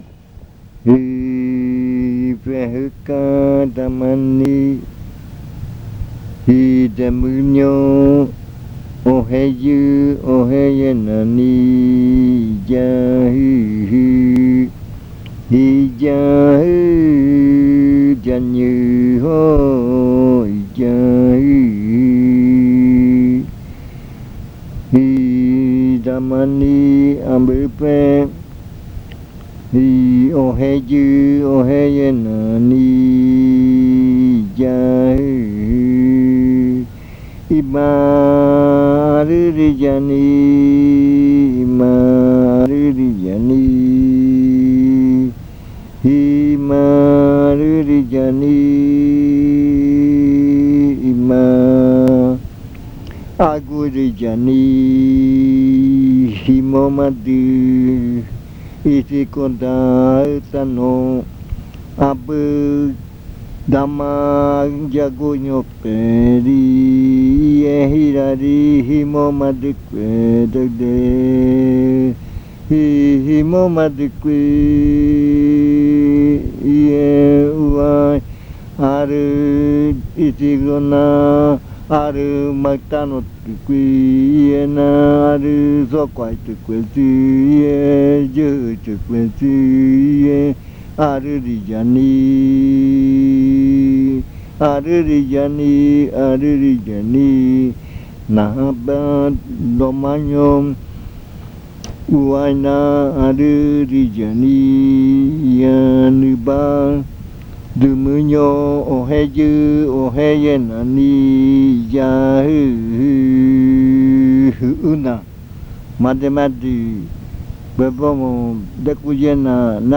Leticia, Amazonas
Canción hablada (uuriya rua). Dice la canción: soy el hombre Jimoma.